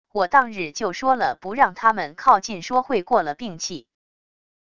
我当日就说了不让他们靠近说会过了病气wav音频生成系统WAV Audio Player